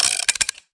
Media:RA_Colt_Evo.wav UI音效 RA 在角色详情页面点击初级、经典和高手形态选项卡触发的音效